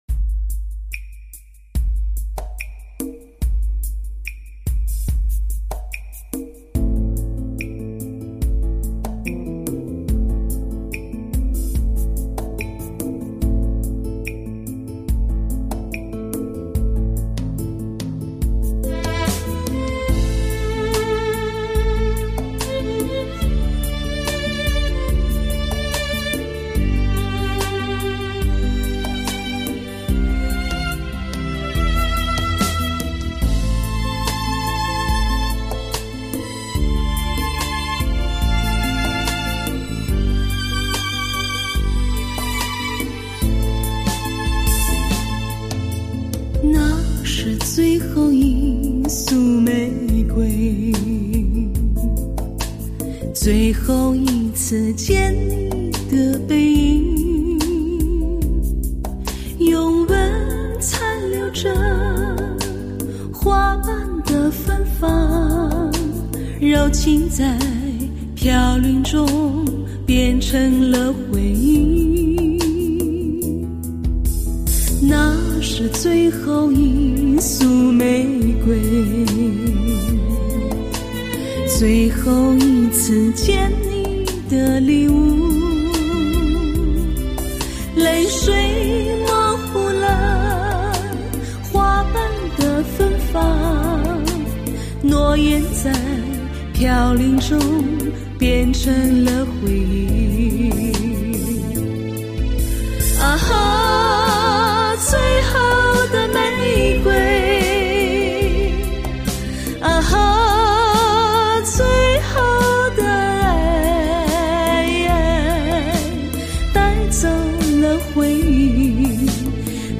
该专辑采用超级模拟录音制作， 宛如黑胶唱片般甜美而自然的声音....